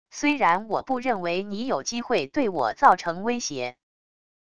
虽然我不认为你有机会对我造成威胁wav音频生成系统WAV Audio Player